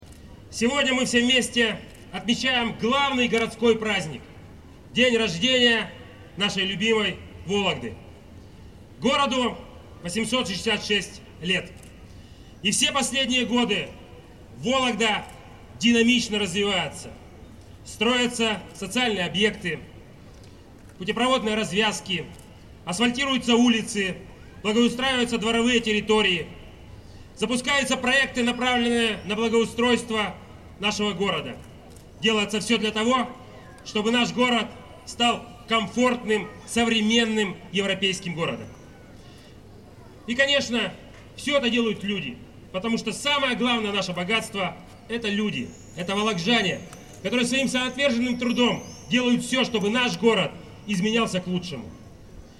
Олег Кувшинников поздравляет вологжан с Днем города
Также губернатор поблагодарил всех жителей областной столицы за вклад в благоустройство города. Вологжане ответили ему дружными аплодисментами.